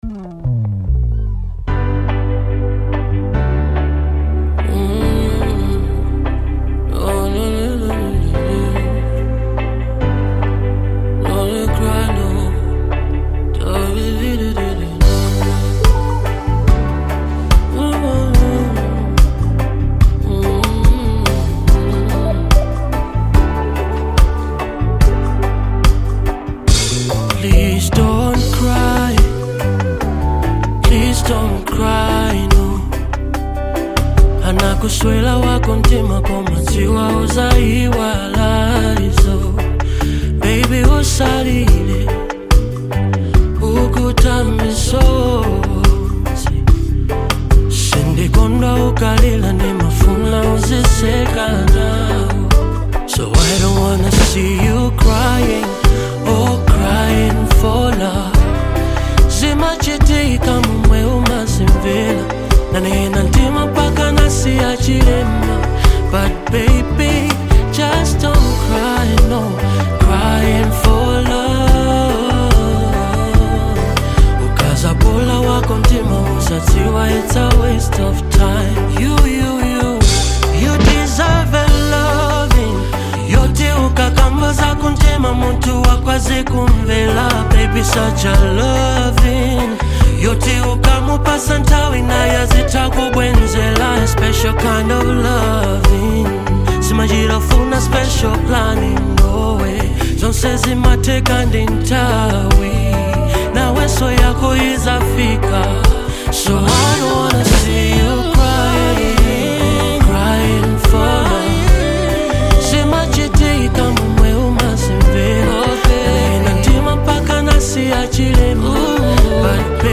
Genre : Afro Beat
In this heartfelt ballad
soulful vocals
Through evocative lyrics and a tender delivery